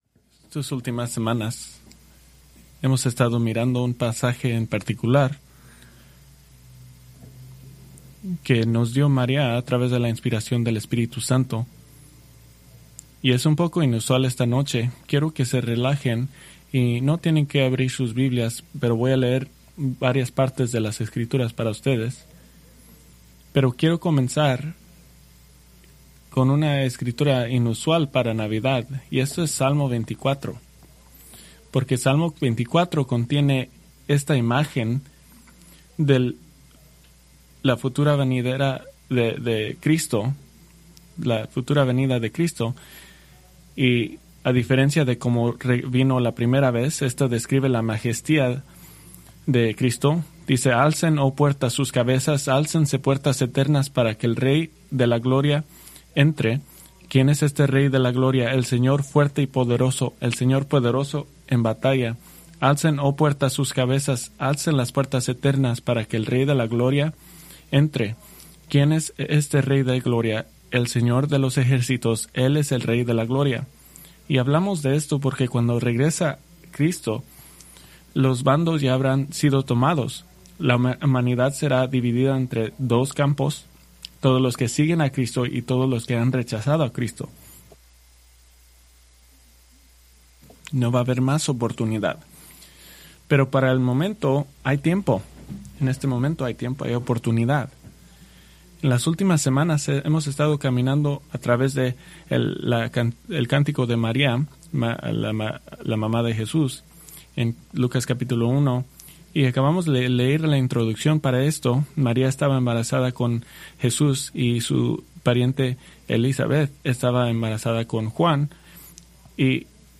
Preached December 24, 2025 from Lucas 1:56